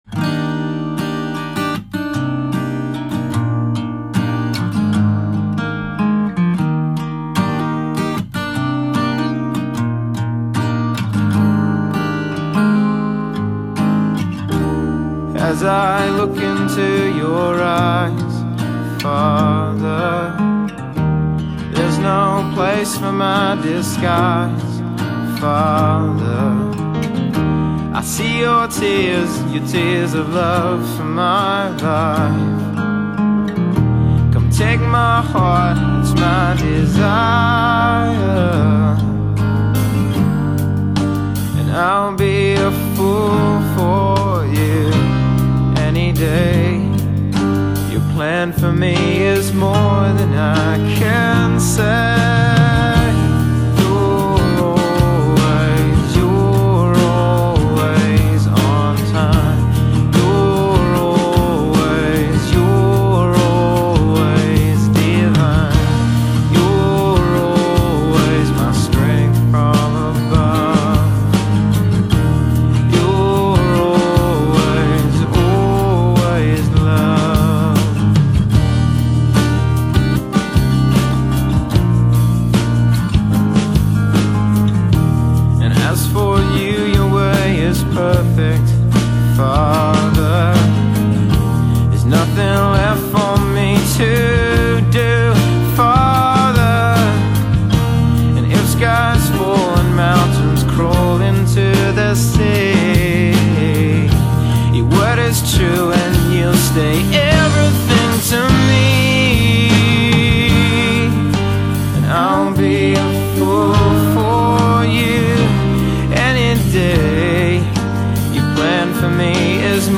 christian artist devotional worship worship leader